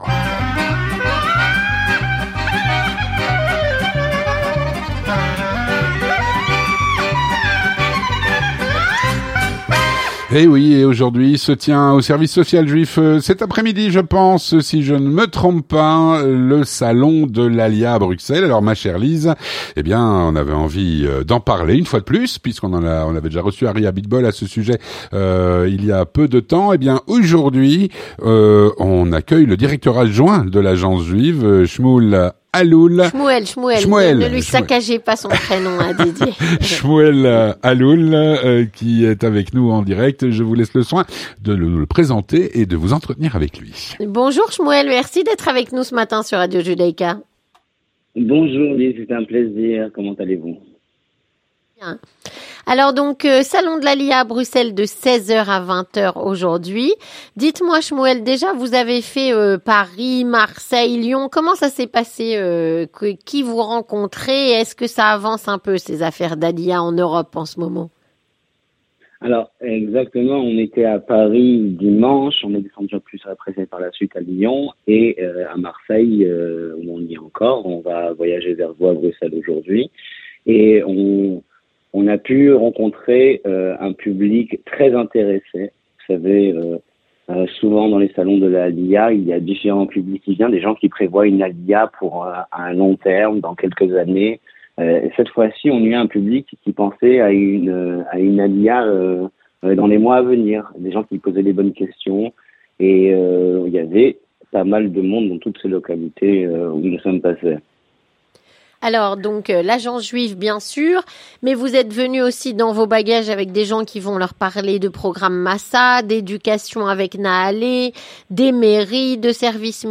3. L'interview communautaire